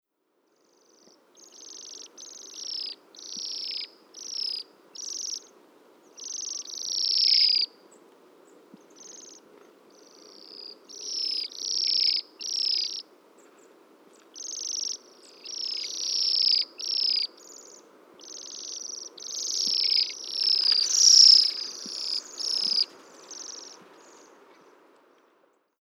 На этой странице собраны записи пения свиристелей – птиц с удивительно нежным и мелодичным голосом.
Очаровательное пение свиристеля